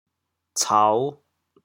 嘈 部首拼音 部首 口 总笔划 14 部外笔划 11 普通话 cáo 潮州发音 潮州 cao5 文 潮阳 cao5 澄海 cao5 揭阳 cao5 饶平 cao5 汕头 cao5 中文解释 嘈 <形> 喧闹 [noisy] 耳嘈嘈以失听。
cao5.mp3